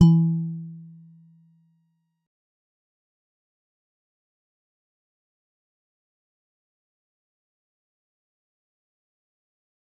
G_Musicbox-E3-mf.wav